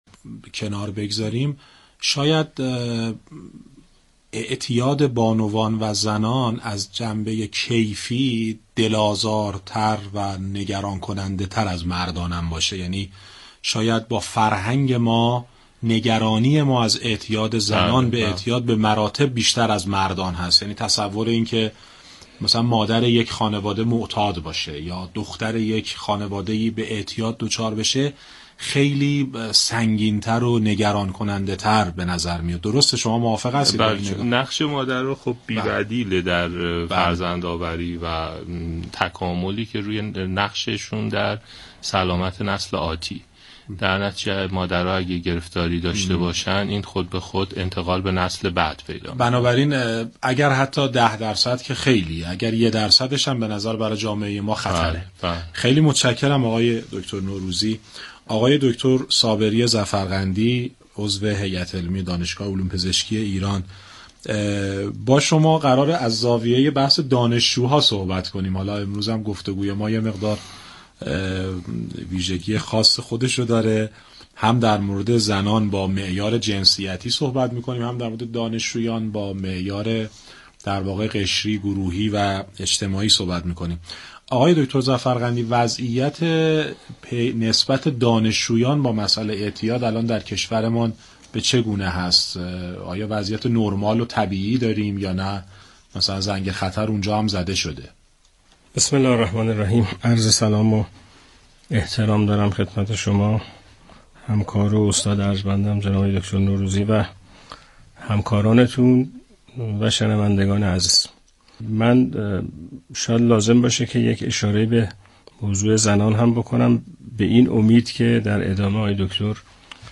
مدتی است برخی امارها از افزایش اعتیاد زنان و دانشجویان در ایران خبر می دهد . در این باره با دو روان پزشک در برنامه گفتاورد گفتگو کردیم .